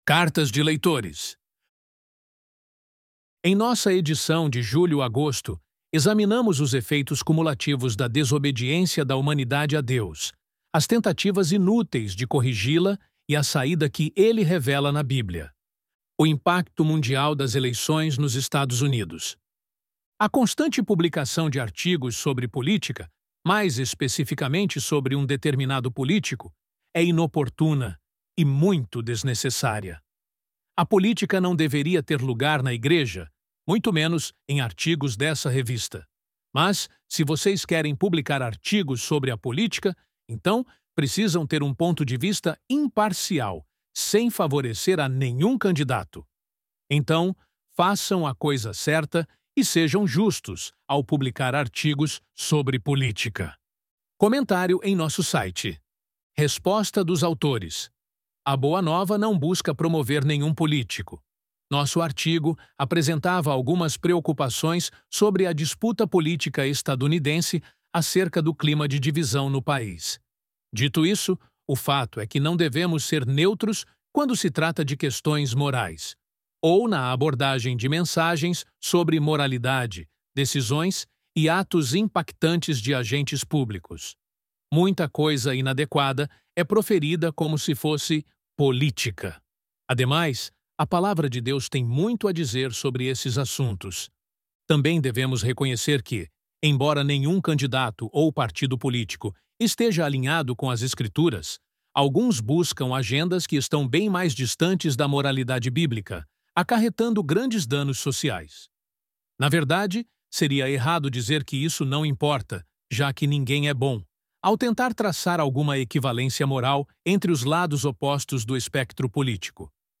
Loading the Elevenlabs Text to Speech AudioNative Player...
ElevenLabs_Cartas_de_Leitores.mp3